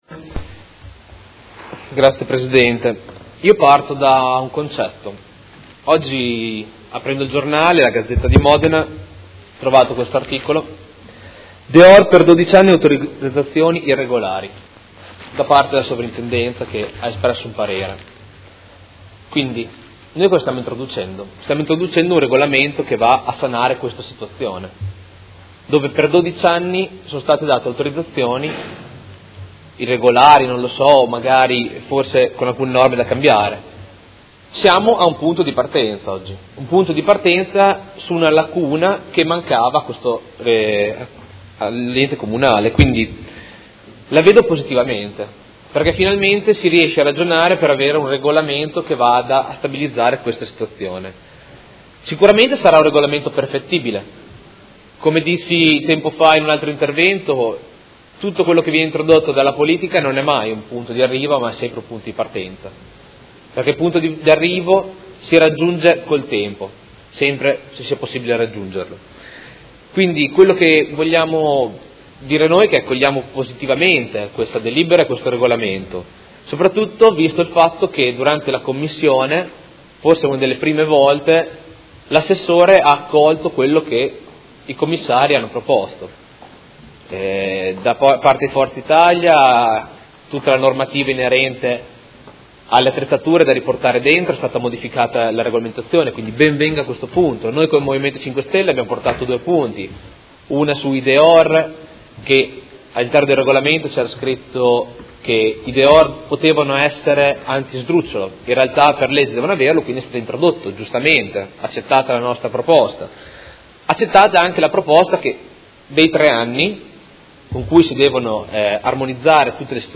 Luca Fantoni — Sito Audio Consiglio Comunale